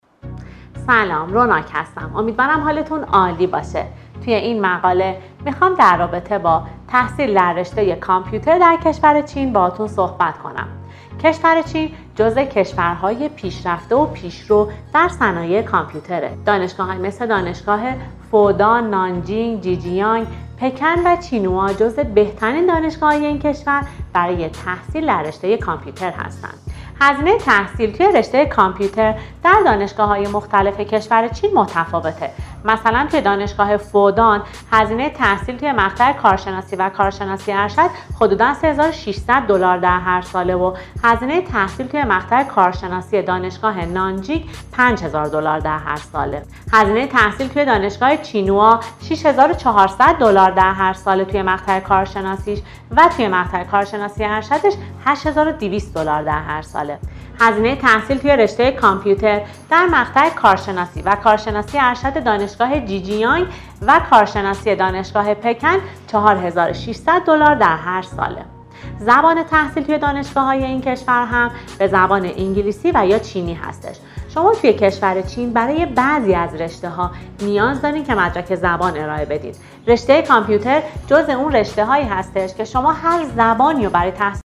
صحبت های کارشناسان ما را تا انتها شنیده و برای هرگونه جزئیات بیشتر با مشاورین ما تماس بگیرید.